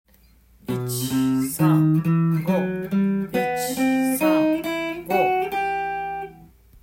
度数練習
更に③はCのコードトーンの３和音に半音下の音階で